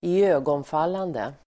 Uttal: [²i'ö:gånfal:ande]